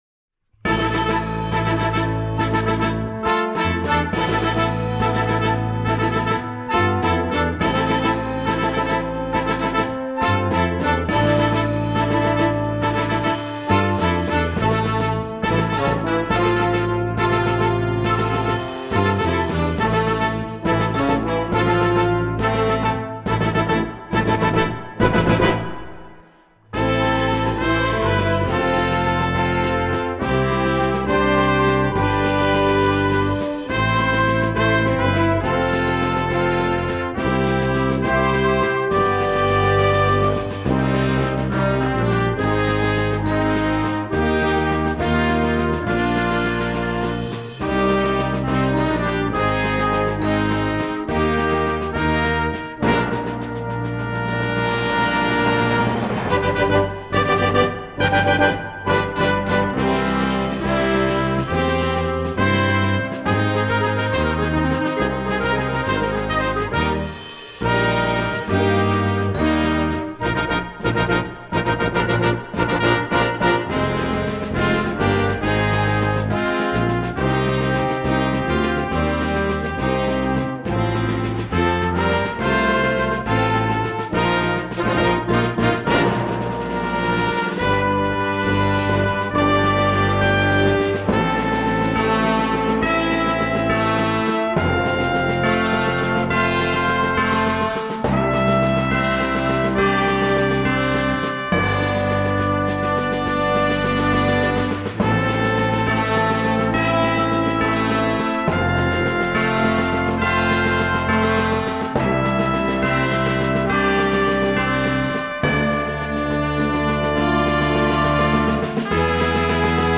für Blasorchester